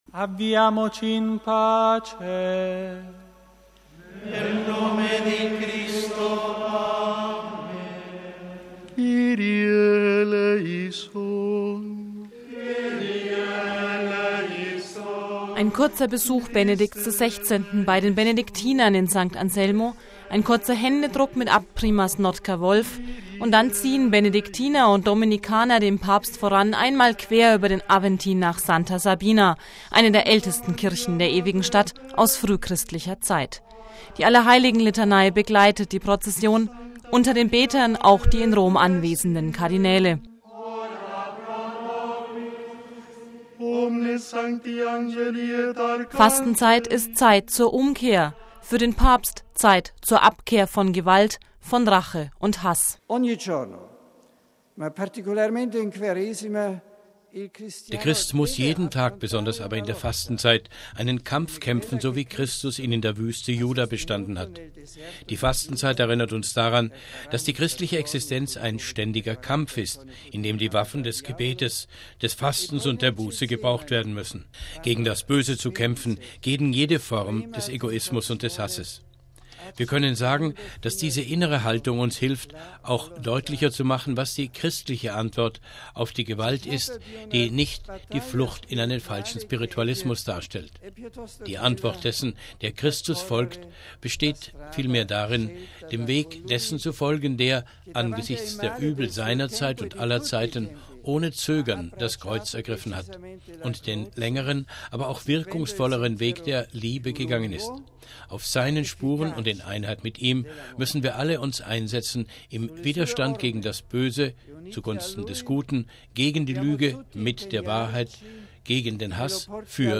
Die Aschermittwochs-Liturgie begann am späten Nachmittag mit der Bußprozession auf dem römischen Aventin:
Die Allerheiligenlitanei begleitet die Prozession, unter den Betern die in Rom anwesenden Kardinäle.